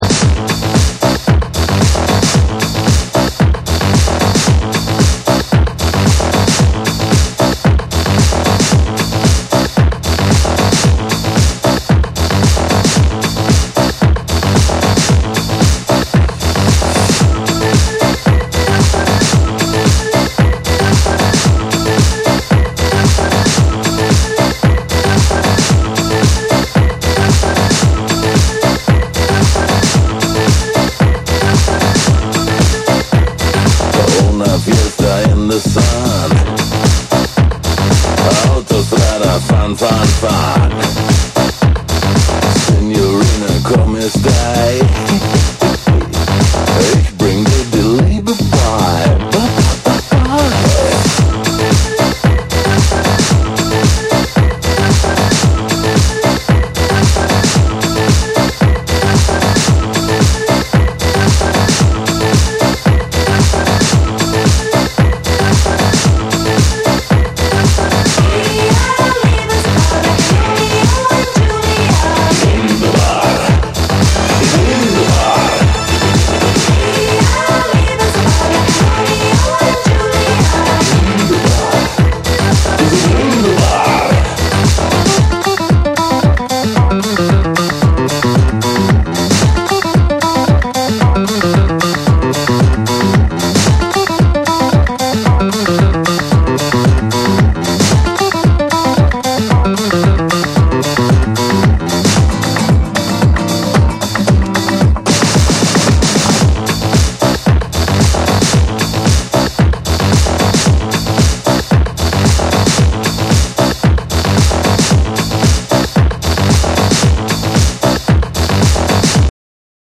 スパニッシュなギターとハンドクラップも登場するディスコ・チューン
DANCE CLASSICS / DISCO / RE-EDIT / MASH UP